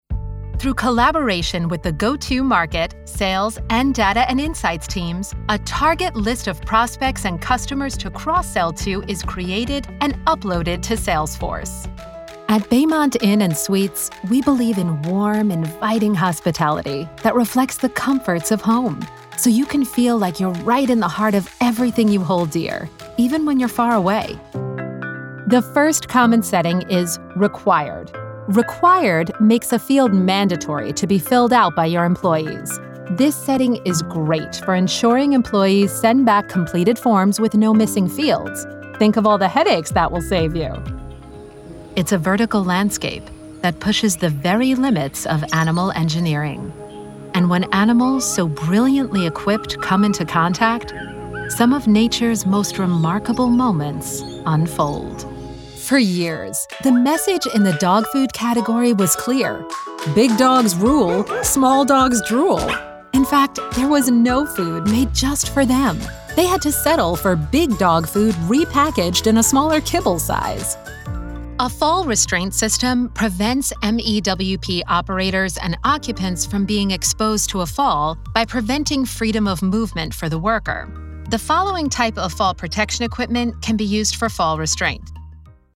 Female
Adult (30-50), Older Sound (50+)
Narration
E-Learning, Documentary
Words that describe my voice are Conversational, Warm, Trustworthy.
All our voice actors have professional broadcast quality recording studios.